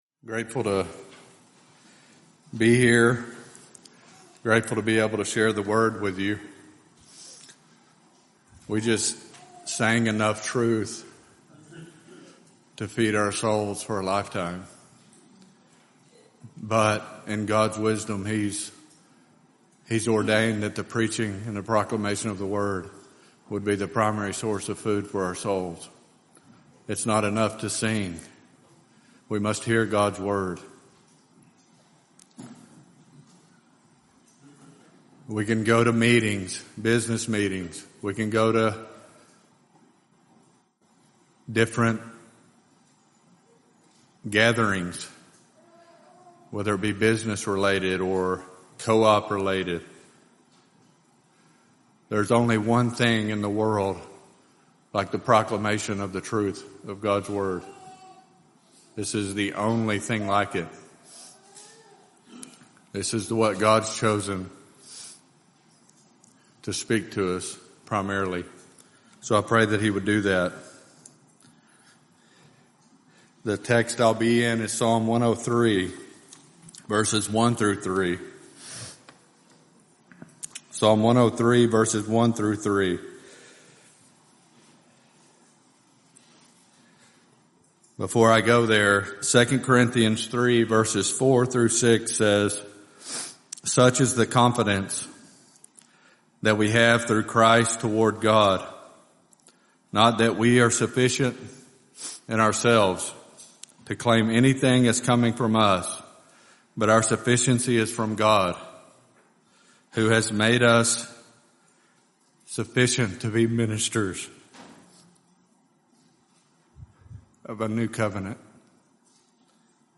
Full Sermons Do you wrestle with your own soul in order to embrace by faith the benefits of the LORD?